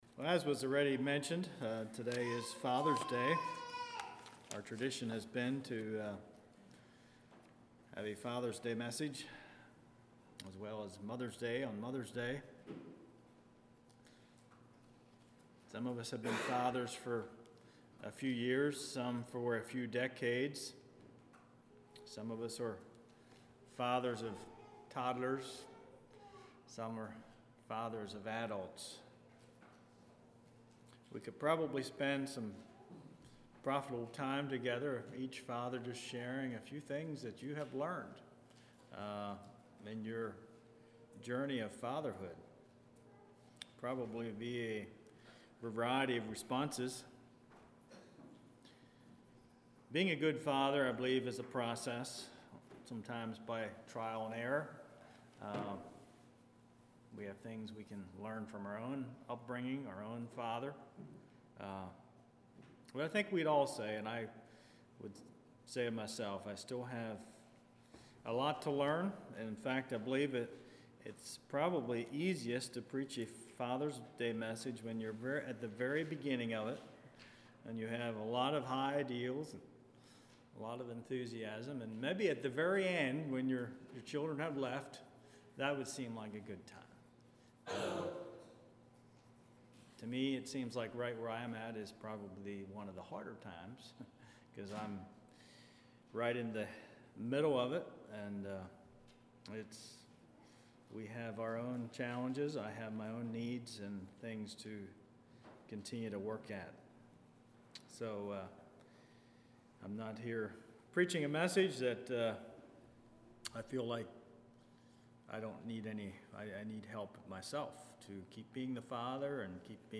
Congregation: Kirkwood